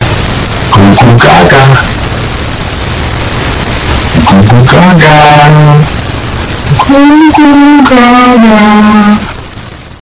Goo Goo Gaga Loud Asf Sound Button - Free Download & Play